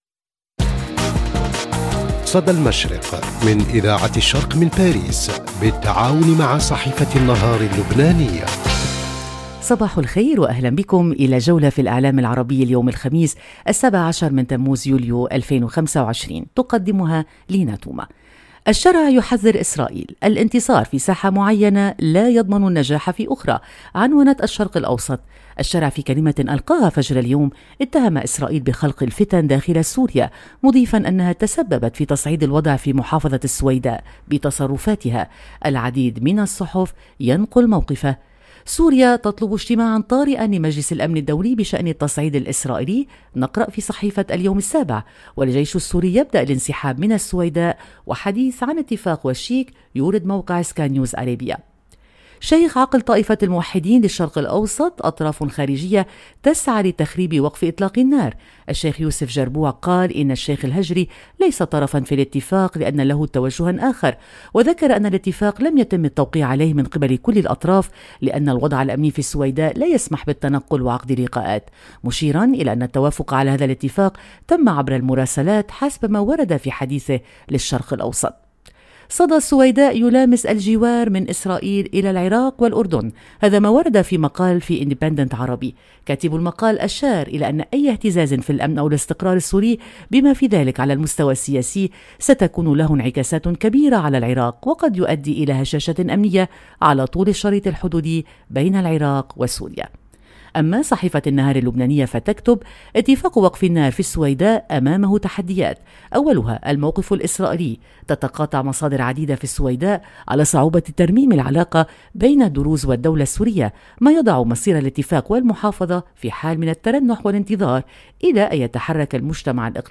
صدى المشرق – نافذتك اليومية على إعلام الشرق، كل صباح على إذاعة الشرق بالتعاون مع جريدة النهار اللبنانية، نستعرض أبرز ما جاء في صحف ومواقع الشرق الأوسط والخليج من تحليلات ومواقف ترصد نبض المنطقة وتفكك المشهد الإعلامي اليومي.